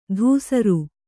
♪ dhūsaru